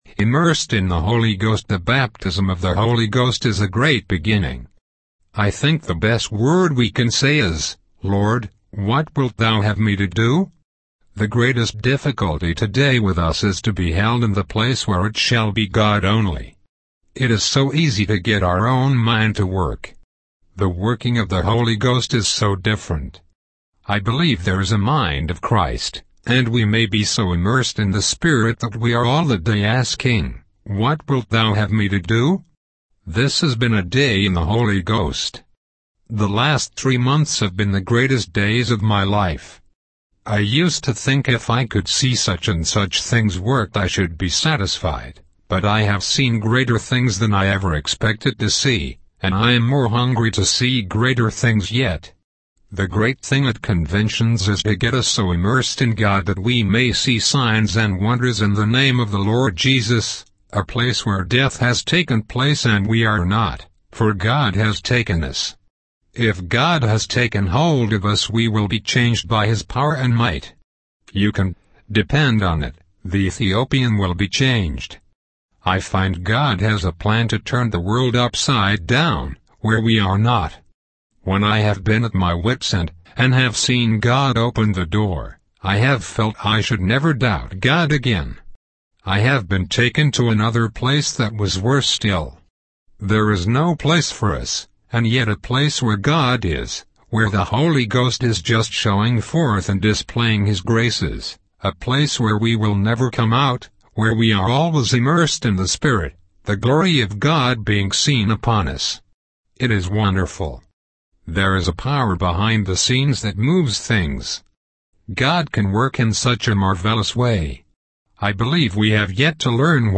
The sermon emphasizes the importance of being immersed in the Holy Ghost and living a life of surrender and dependence on God, allowing Him to work in us to will and to do of His good pleasure.